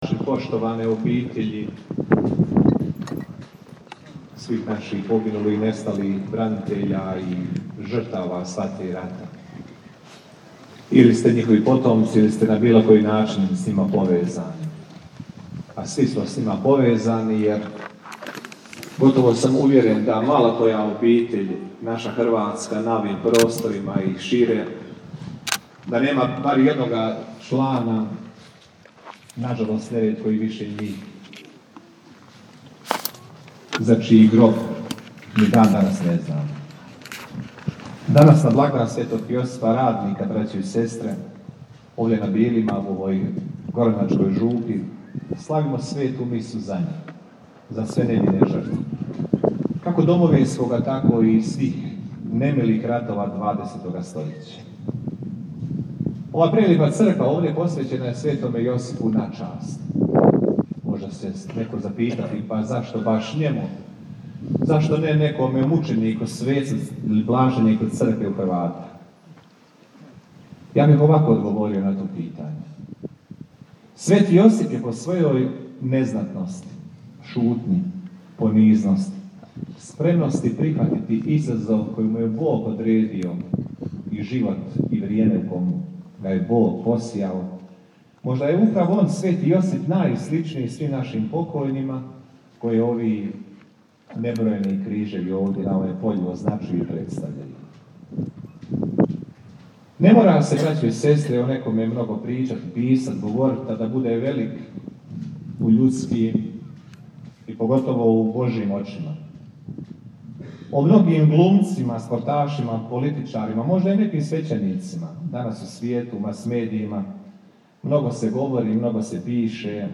Euharistijsko slavlje na Groblju mira na Bilima